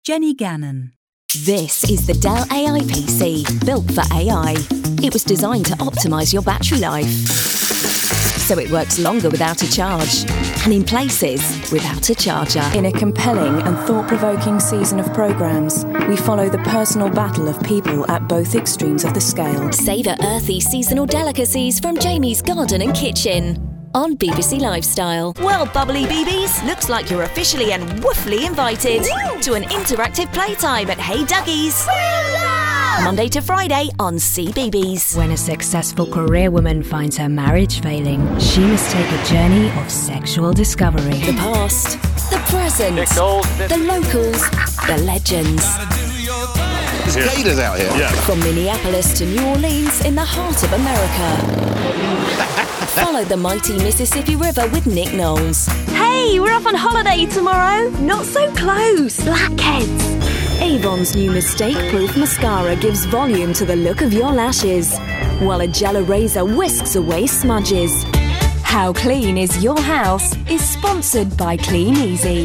Description: London: energetic, playful, exciting
Age range: 20s - 30s
Commercial 0:00 / 0:00
American, Essex, Kent, London*, RP, Scouse